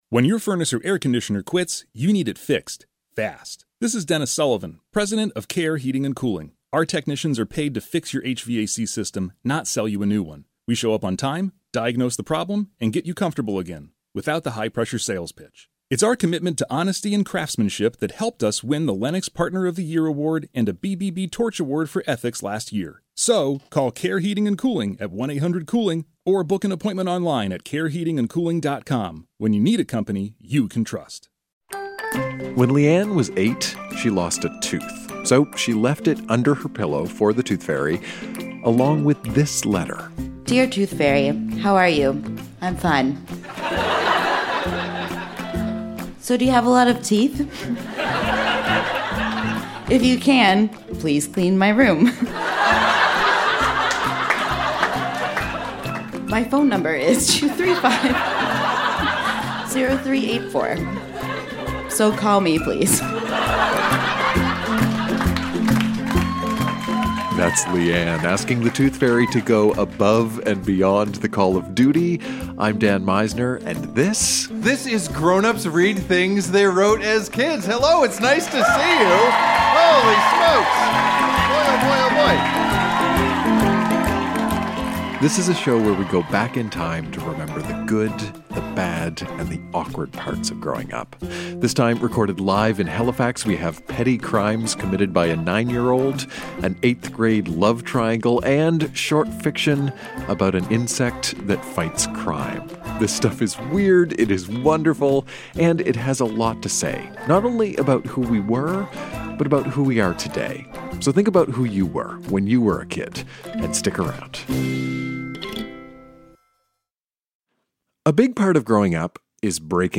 Recorded live at The Company House in Halifax, NS.